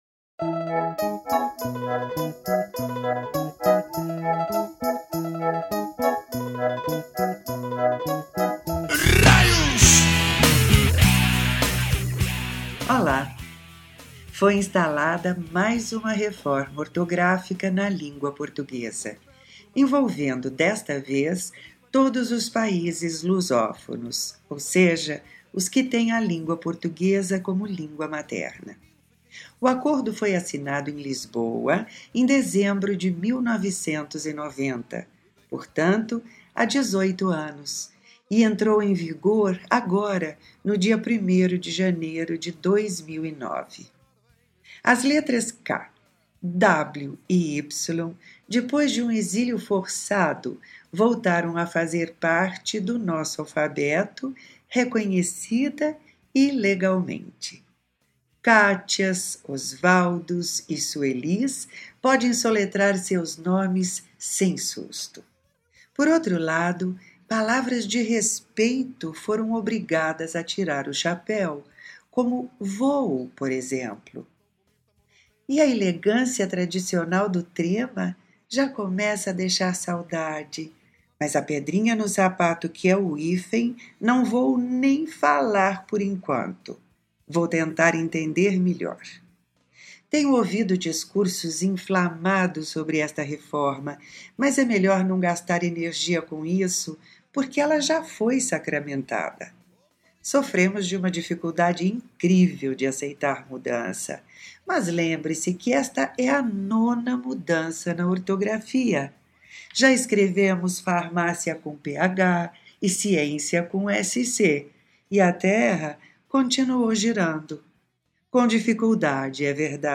De língua e linguagem na voz da autora